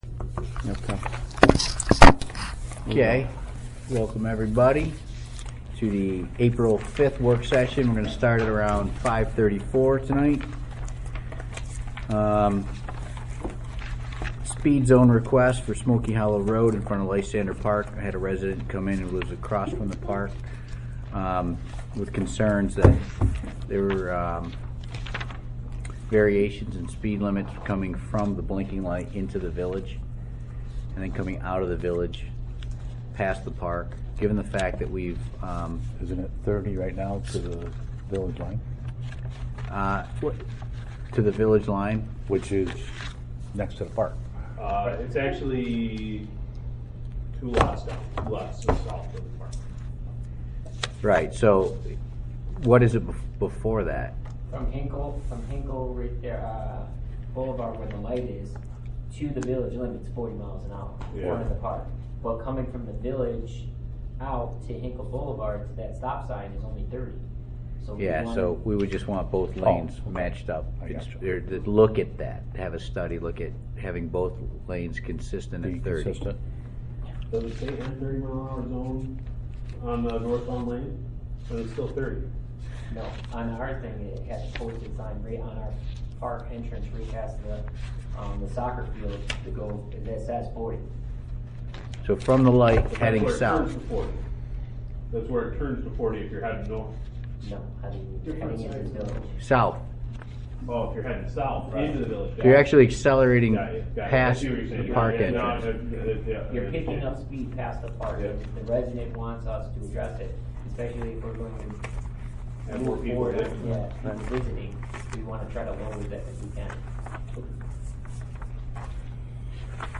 april-5-2018-work-session-sound recording-u.mp3